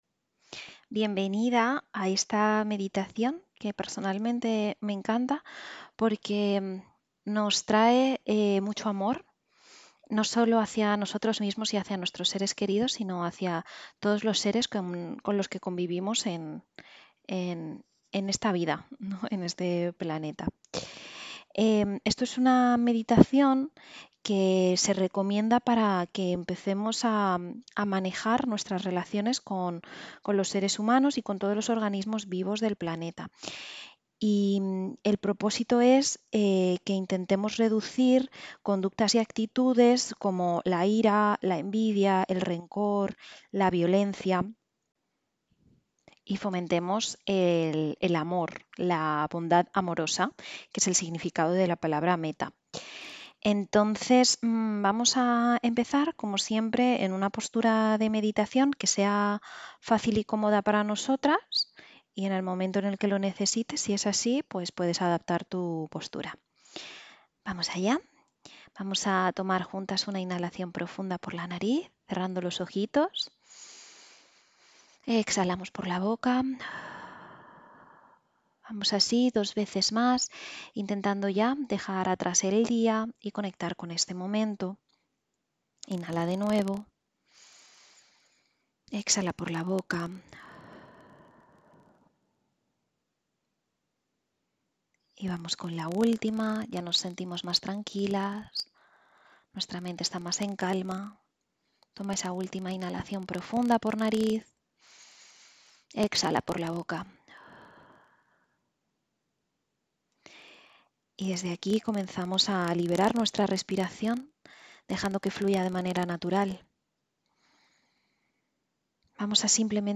Sesión 7: Meditación de Amor y Bondad (Metta)